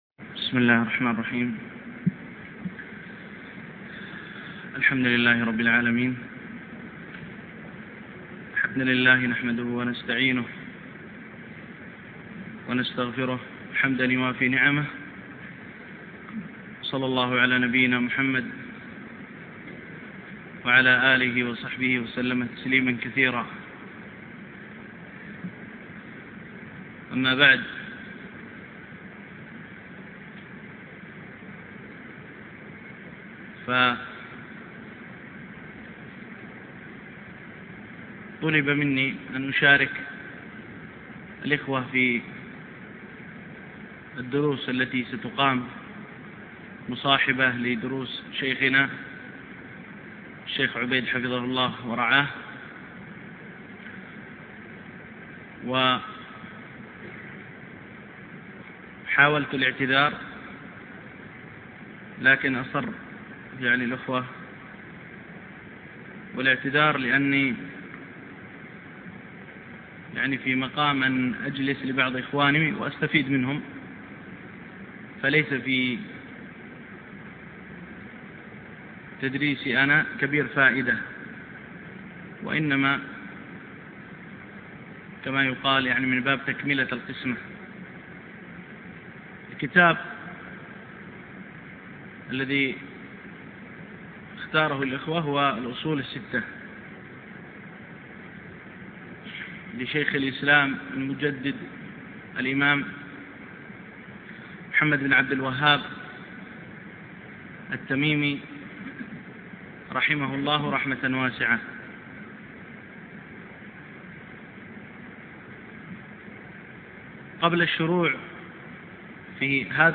شرح الأصول الستة - الدرس الأول